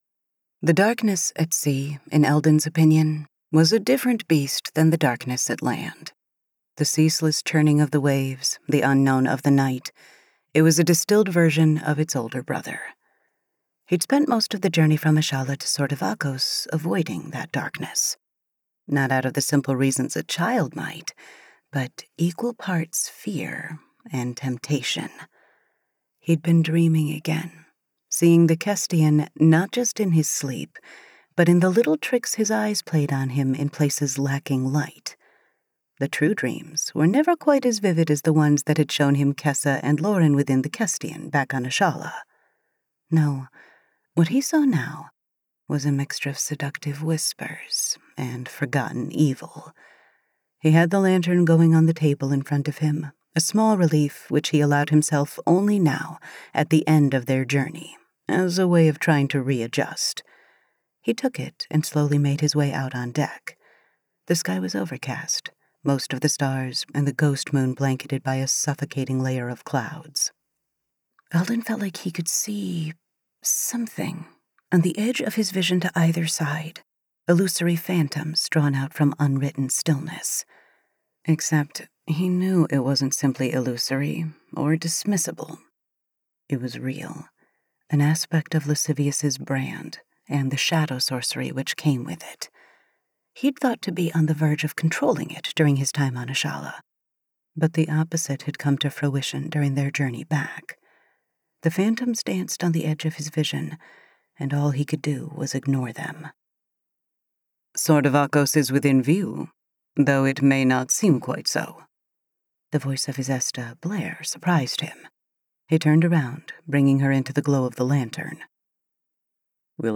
Sin and Sorcery 4 Audiobook